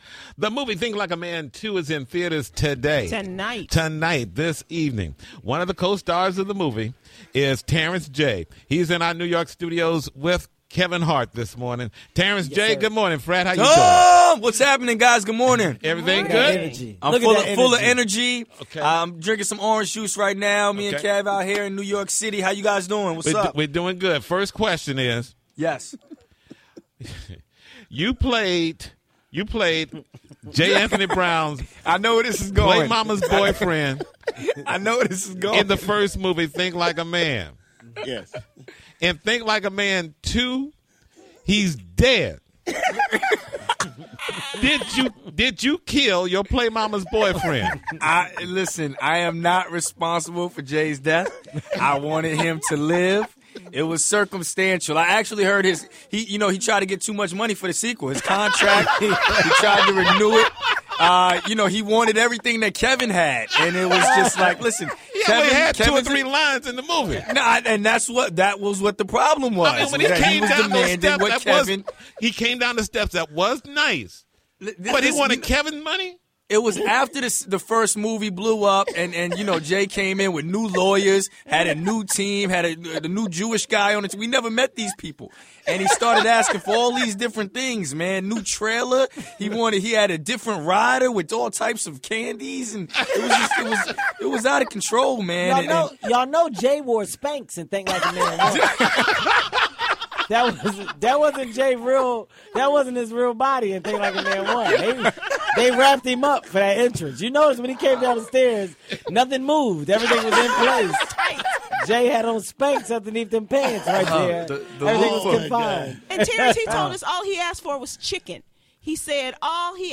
THREE HOURS OF FUNNY: Kevin Hart Co-Hosted The TJMS This Morning! [LISTEN]
With the sequel of his highly anticipated movie, ‘Think Like A Man Too’ only a few hours away, he joins us this morning at our New York studios for an entire three hours — and we’ve got a special friend of Kevin calling into the TJMS.
Kevin’s co-star in ‘Think Like A Man Too’, Terrence J calls in. The E! News host dishes on his current relationship status and his thoughts on love and marriage.